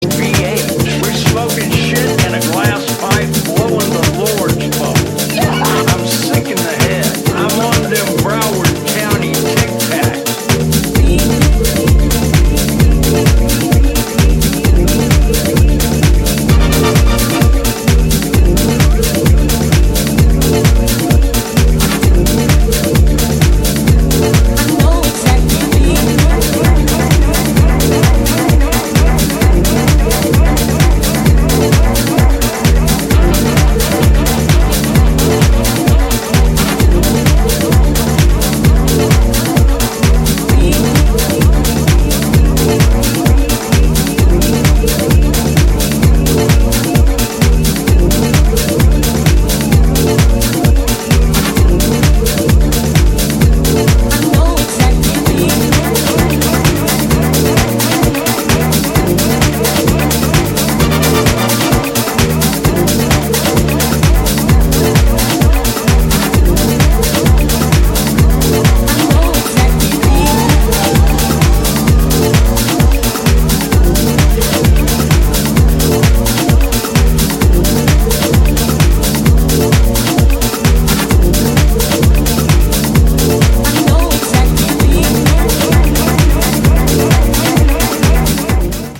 執拗なディスコサンプルの反復とエフェクトの応酬で畳み掛ける、BPM130前後のキラーチューン全4曲を収録。